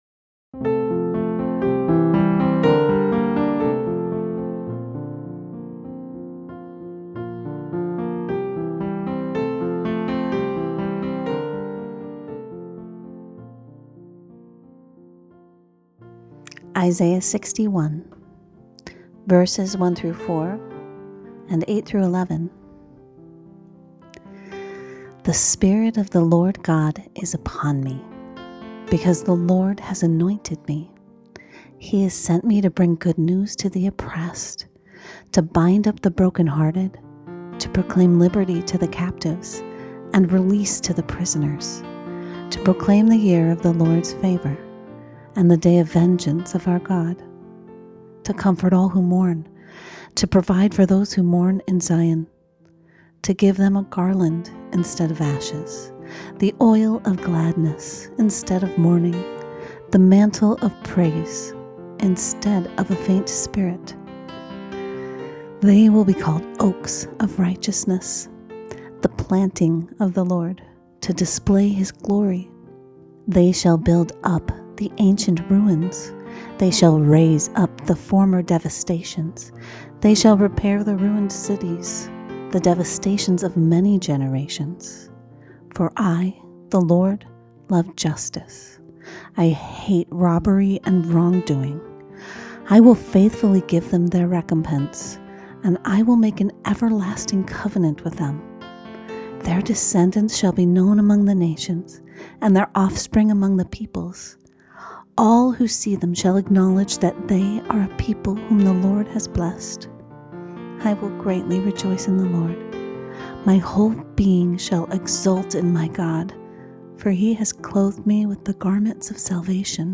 Scriptures only for Advent Cycle B Third Sunday of Advent
third-sunday-scripture-only.mp3